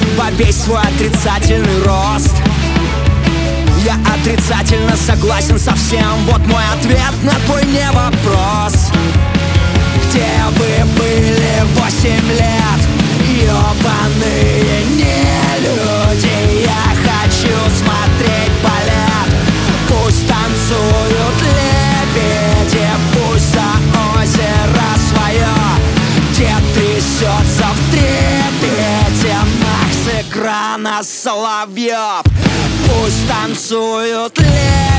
Rock Alternative Punk